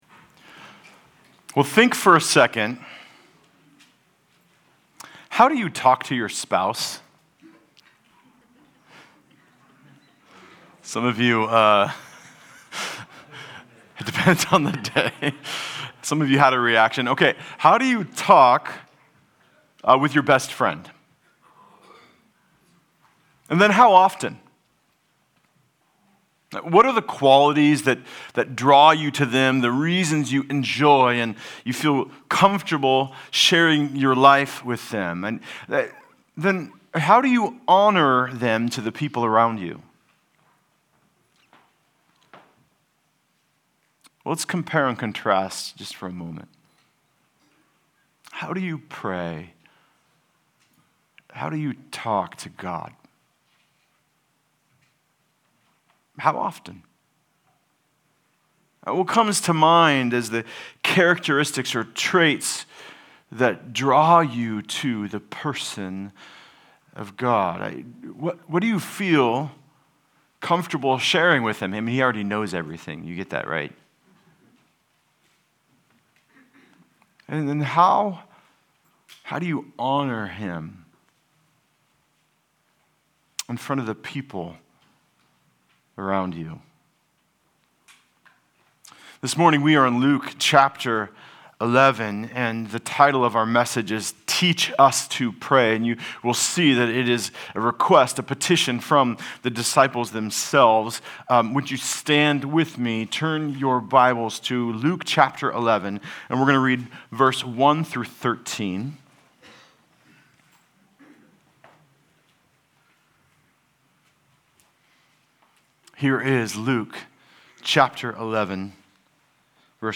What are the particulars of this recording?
Passage: Luke 11:1-13 Service Type: Sunday Service